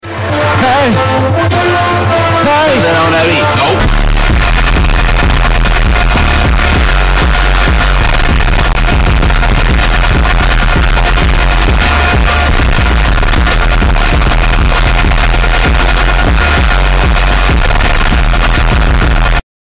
cat sound effects free download